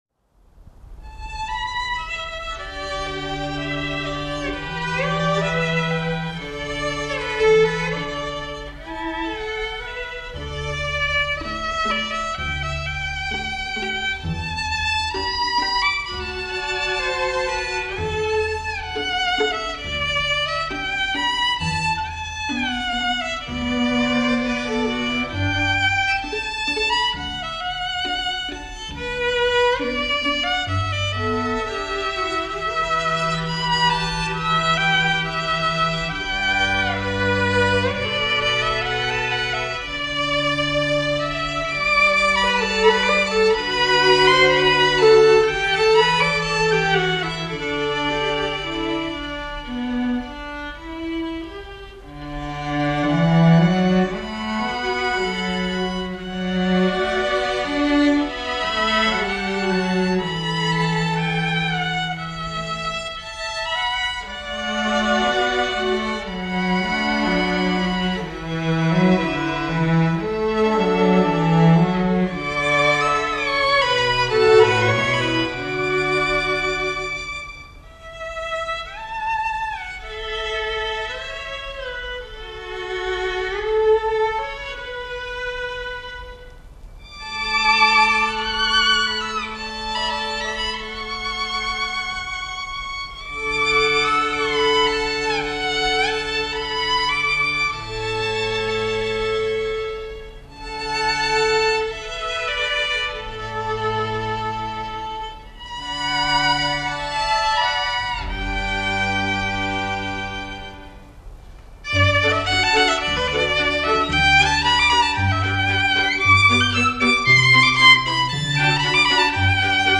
2009年7月由原版母带數碼修版複制